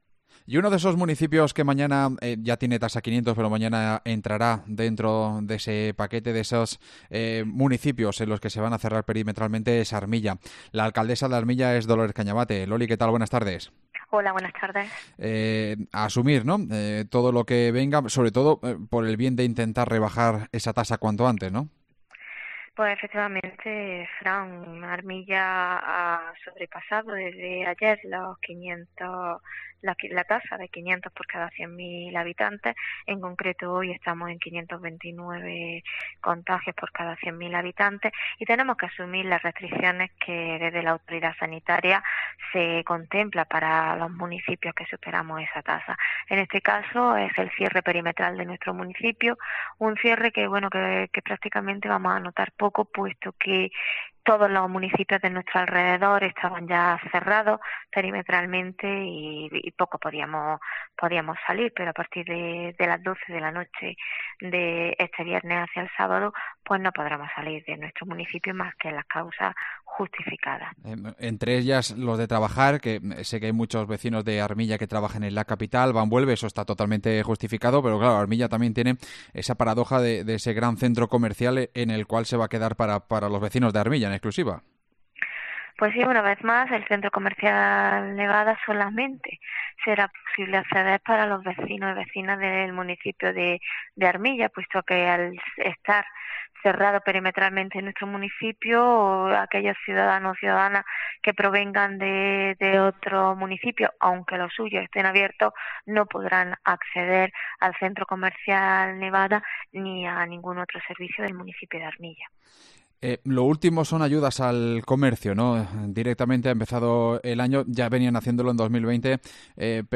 Hablamos con su alcaldesa Loli Cañavate